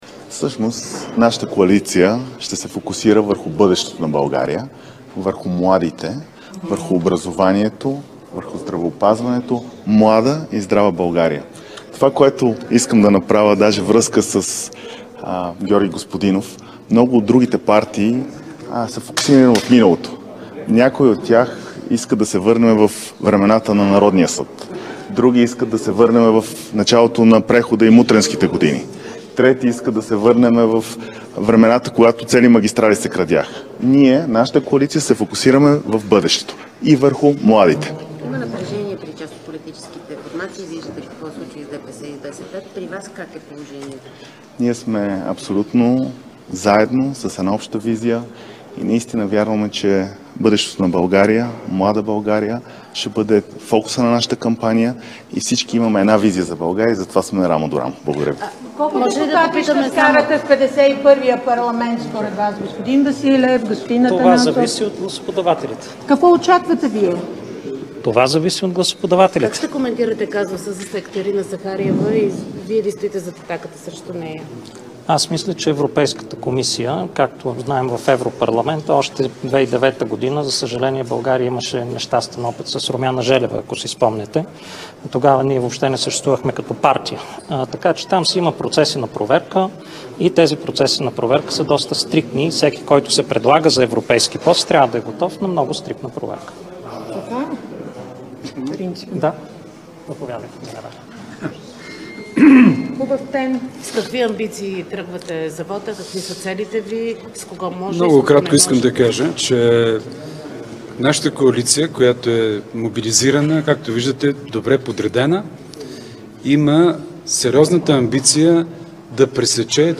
Директно от мястото на събитието
14.00 - Брифинг на Централната избирателна комисия във връзка с подготовката и произвеждането на изборите за народни представители, насрочени за 27 октомври 2024 г.. - директно от мястото на събитието (сградата на НС)